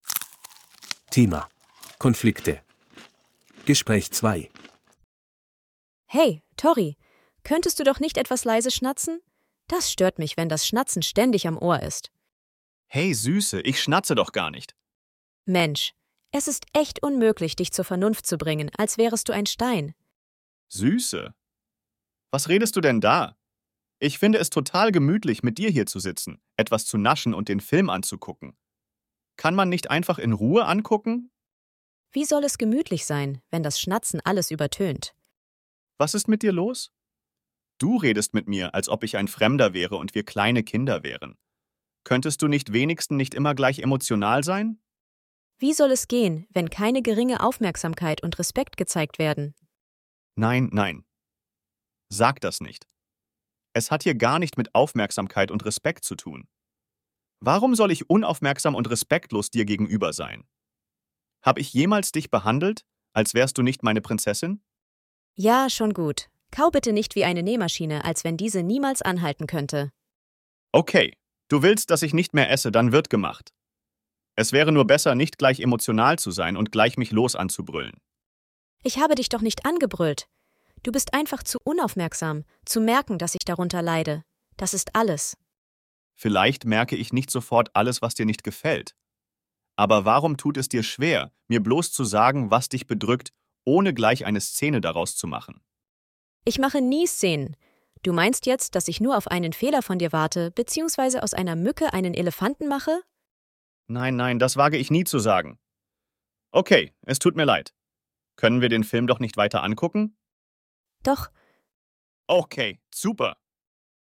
Bài nghe hội thoại 2:
B1-Registeruebung-8-Konflikte-Gespraech-2.mp3